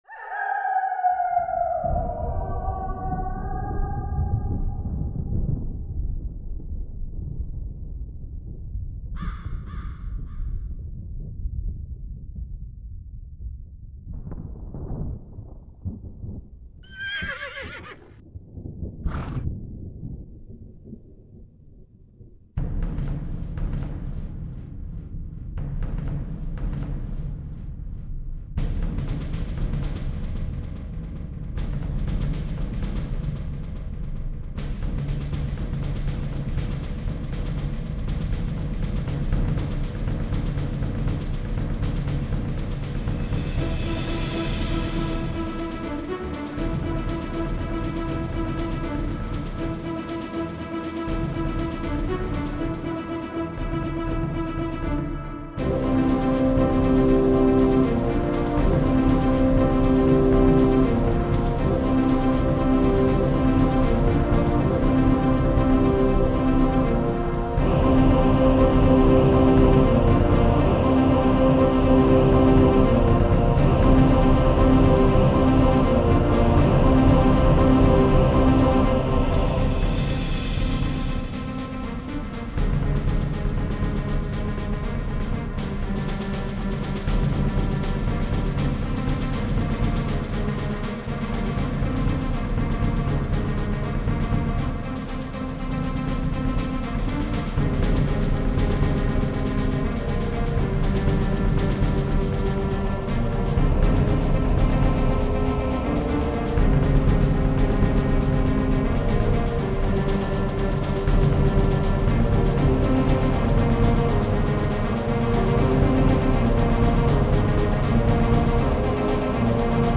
Sound effects, all very good, but I base my points on music!